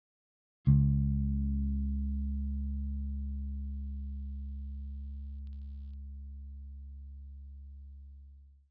Elektronik brummt, Fender Preci PJ Precision Plus
Anbei eine Aufnahme des Problems. Gleichzeitig: Entwarnung, Asche über mein Haupt, der Dümmste gibt einen aus und zwar 'ne Lokalrunde: Tatsächlich war es der A/D-Wandler des Audio-Interfaces.
Mein Gefühl, dass es eine leichte digitale "Verzerrung" ist, war also doch richtig.